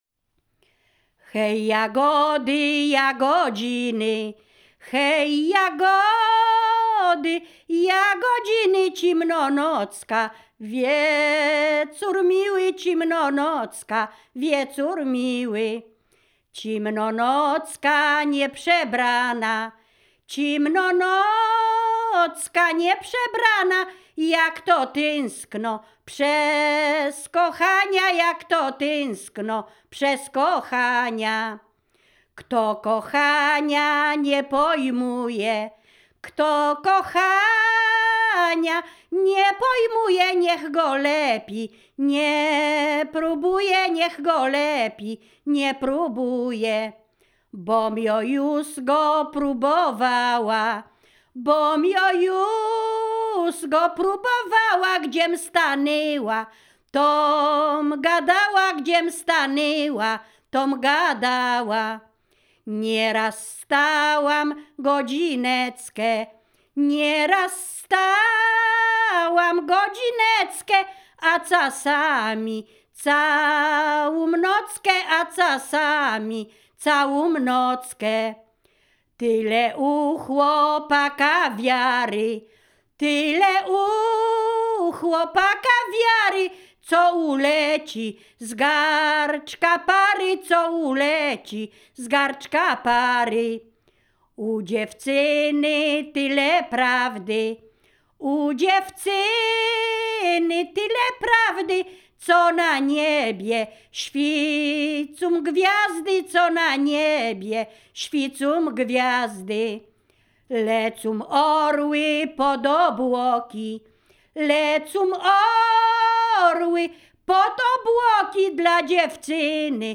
liryczne miłosne